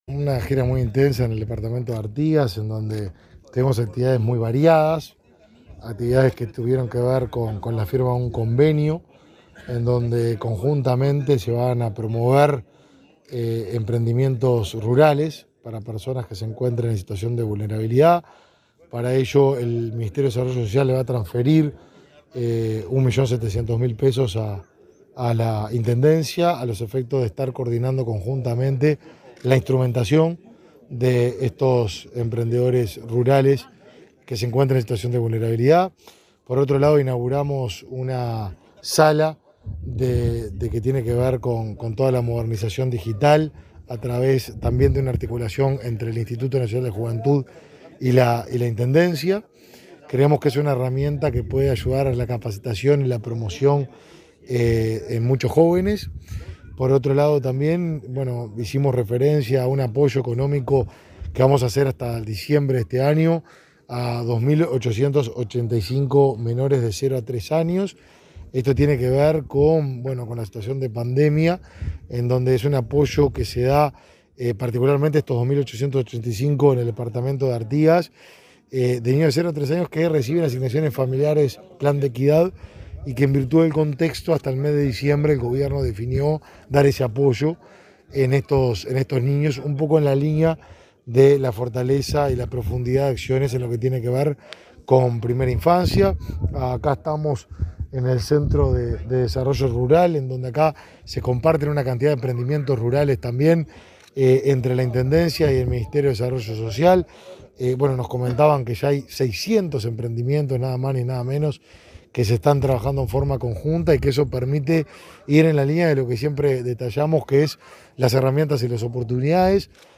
Entrevista al ministro de Desarrollo Social, Martín Lema
El ministro Martín Lema recorrió, este 19 de octubre, el departamento de Artigas. En ese marco, efectuó declaraciones a la Comunicación Presidencial.